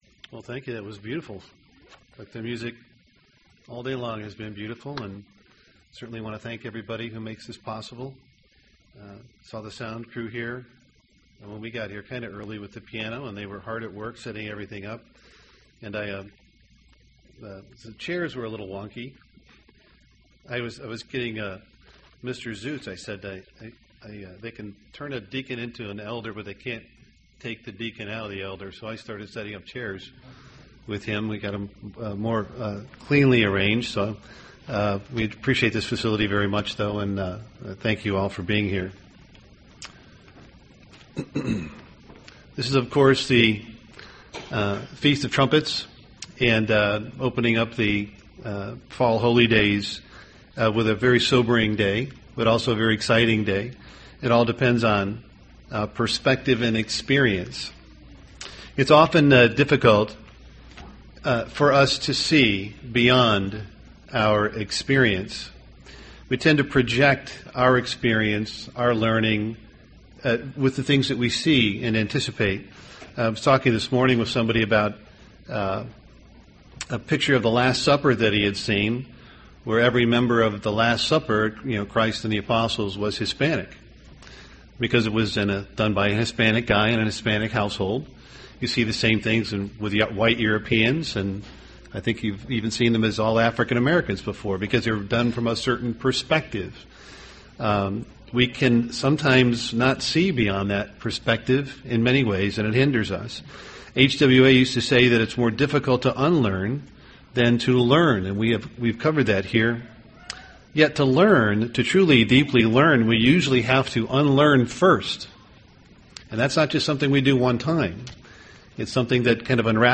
UCG Sermon Feast of Trumpets Governments governments impact on morality Studying the bible?